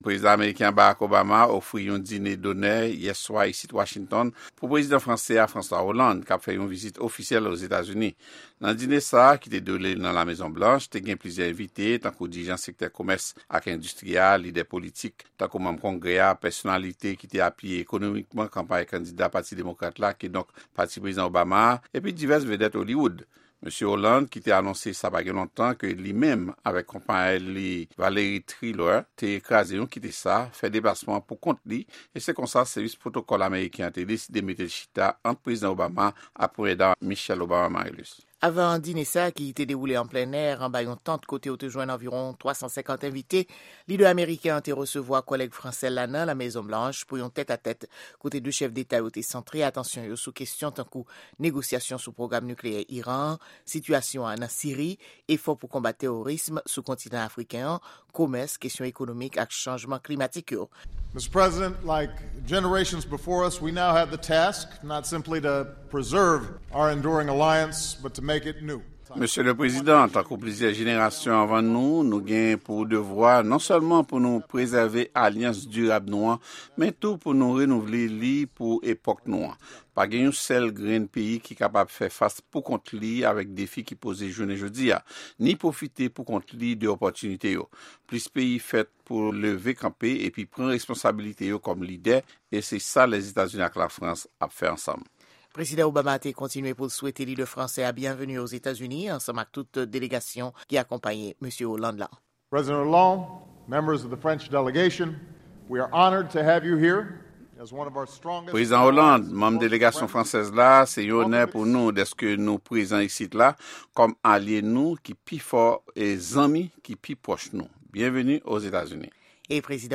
Repòtaj sou Somè Obama-Hollande nan La Mezon Blanch - Madi 11 fev. 2014